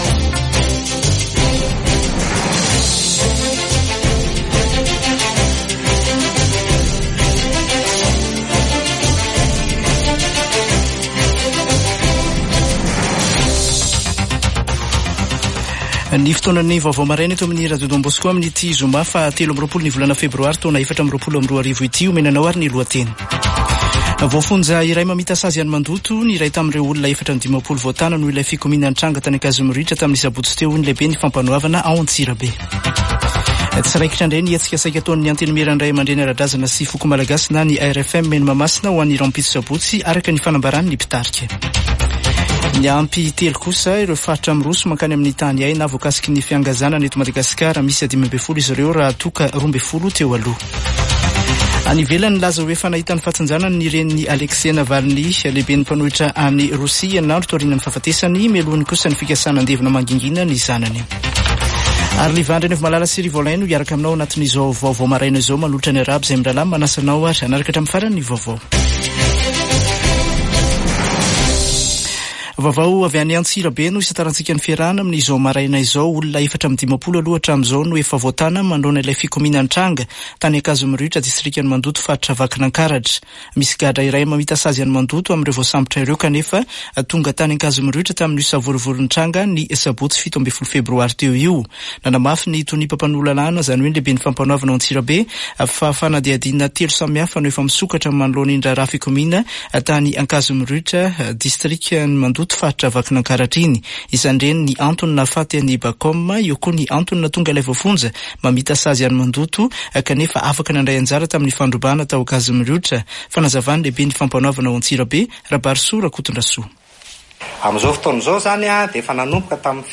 [Vaovao maraina] Zoma 23 febroary 2024